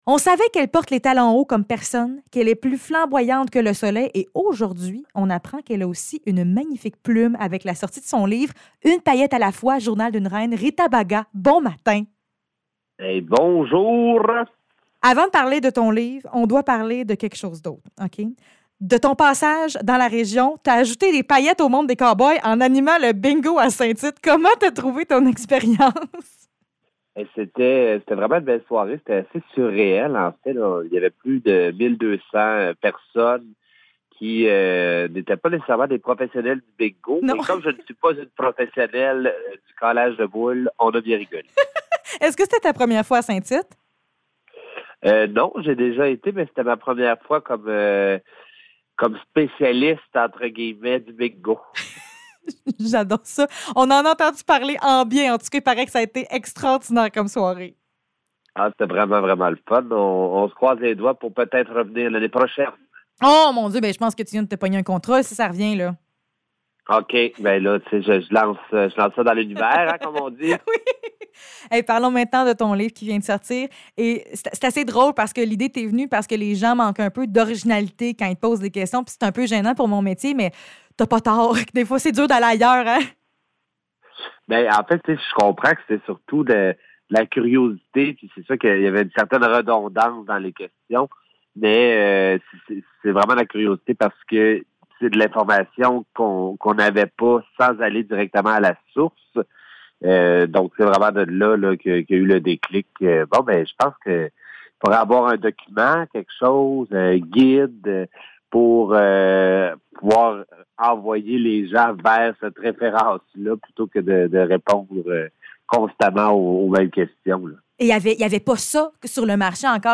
Entrevue avec Rita Baga